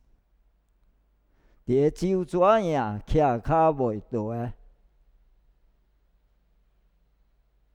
35潮州纸影